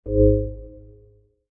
biometric_register_ready.ogg